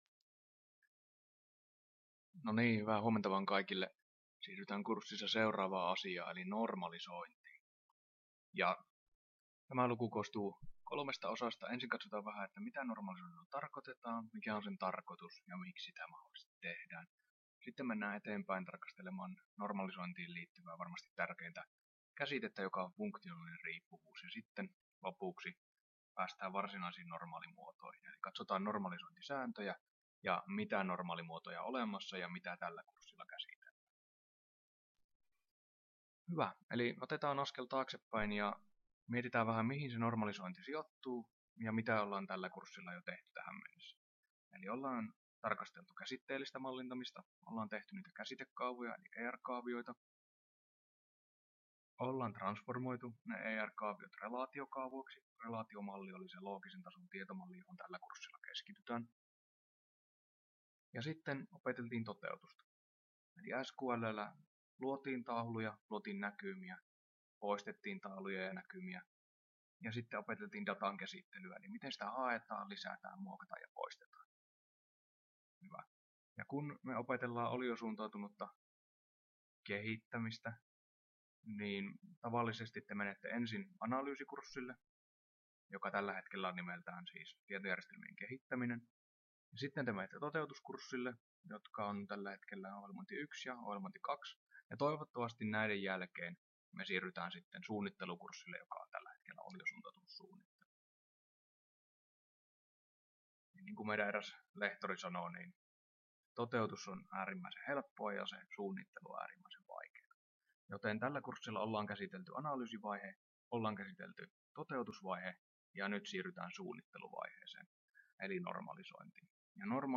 Luento 11 — Moniviestin